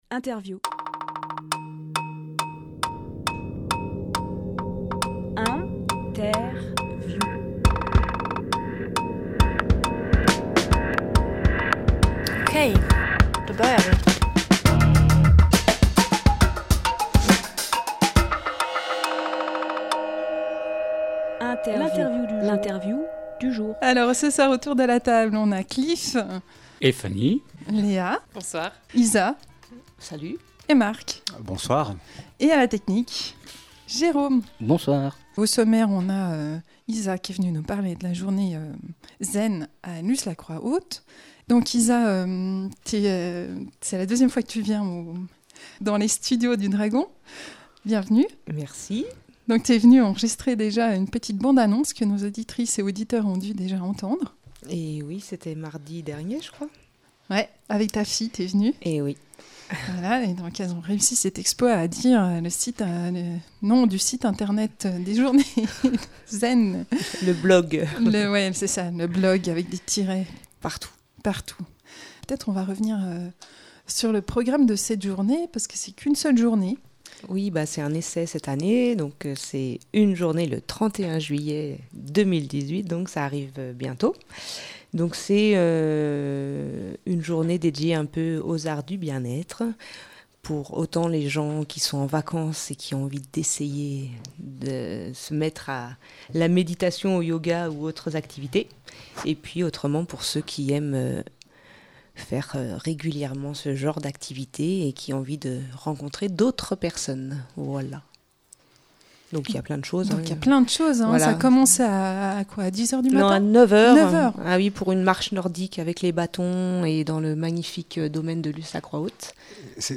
Emission - Interview Journée Zen à Lus-la-Croix-Haute Publié le 23 juillet 2018 Partager sur…